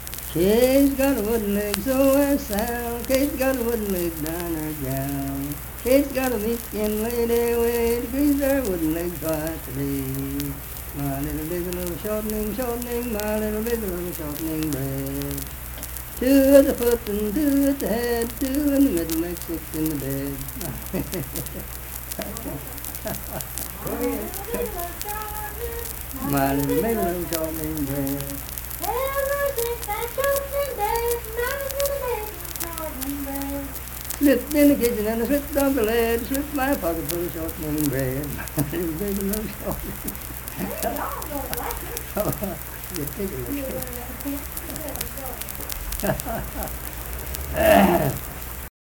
Unaccompanied vocal music
Minstrel, Blackface, and African-American Songs
Voice (sung)
Harts (W. Va.), Lincoln County (W. Va.)